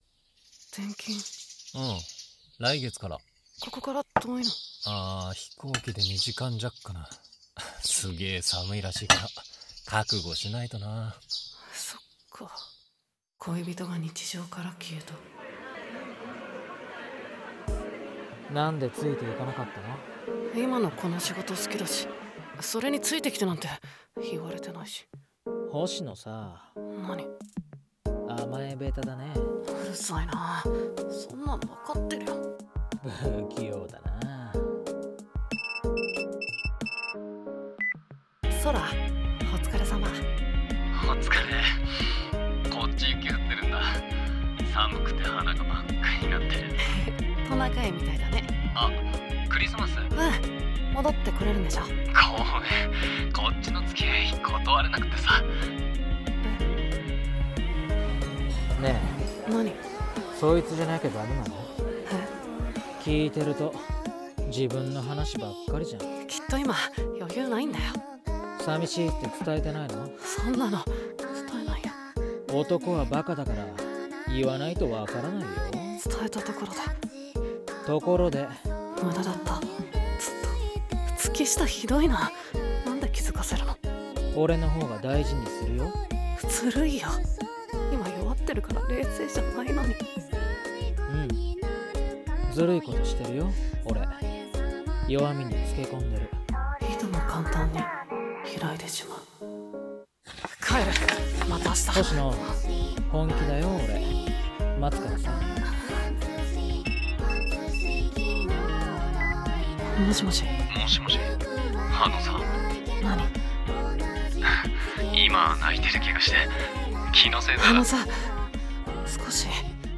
3人声劇